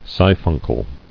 [si·phun·cle]